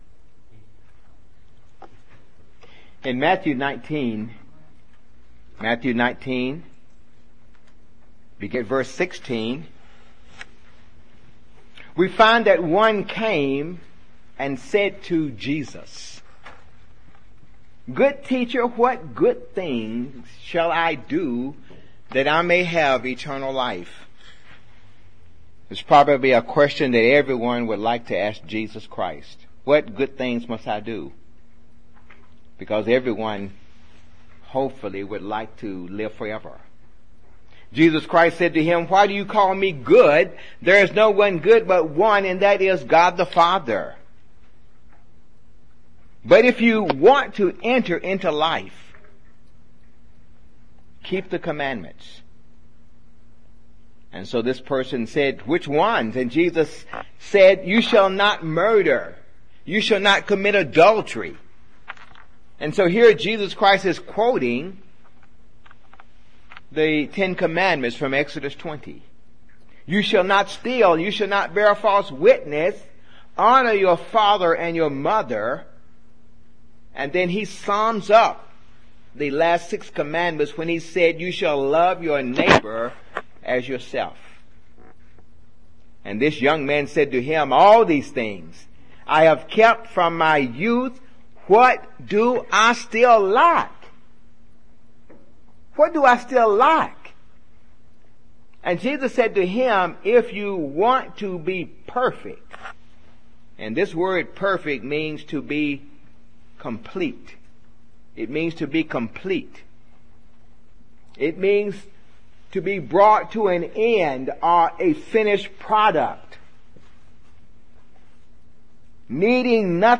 The path to eternal life is a very narrow path. This sermon examines how to find and follow that path.
Given in Jackson, TN